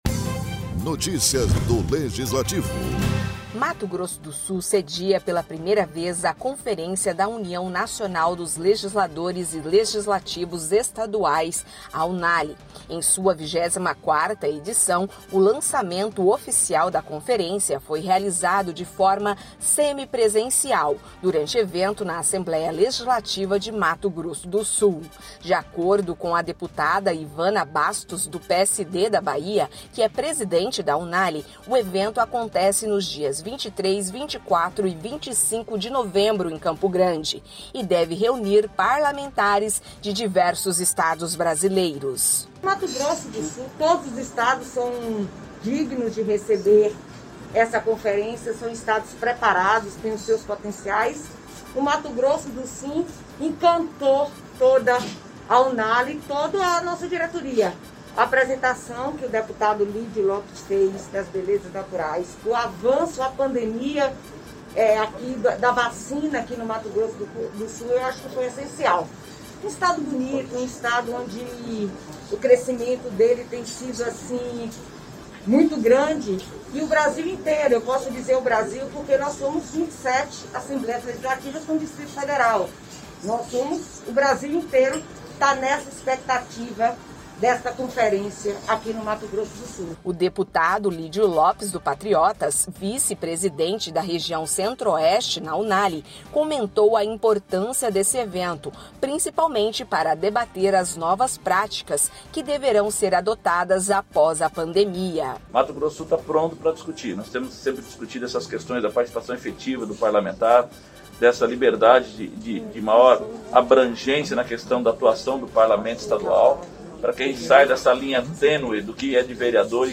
Produção e Locução: